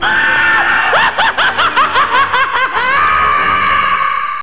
Evil Laugh Sound Effect Free Download
Evil Laugh